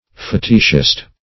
Search Result for " fetichist" : The Collaborative International Dictionary of English v.0.48: Fetichist \Fe"tich*ist\, Fetishist \Fe"tish*ist\, n. A believer in fetiches.